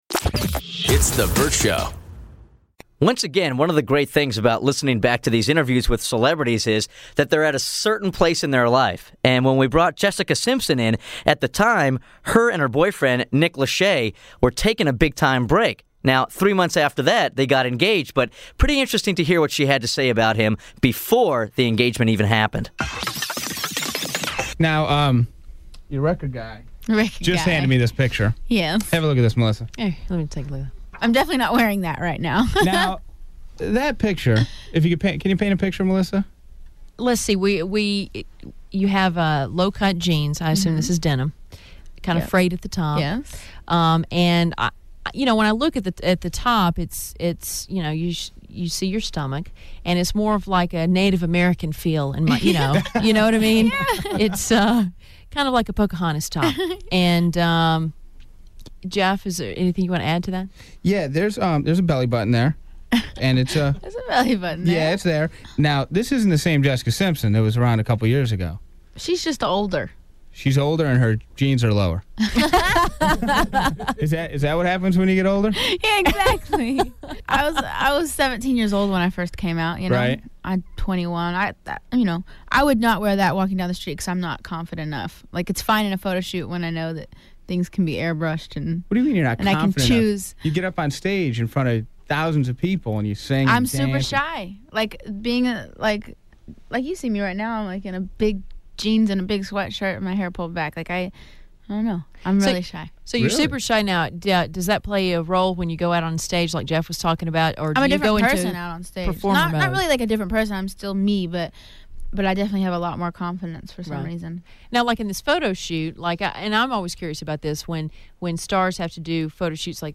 Vault: Interview Jessica Simpson